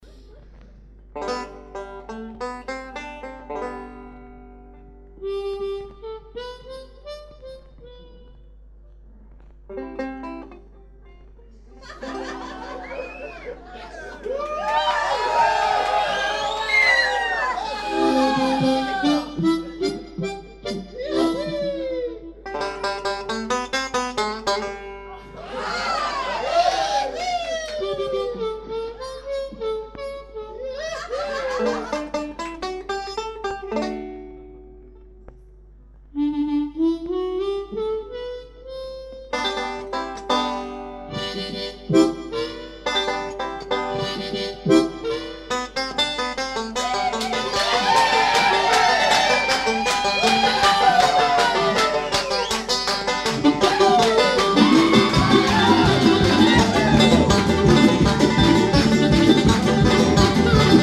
D'influences country, rock et celtique
Simple, efficace et très vivant.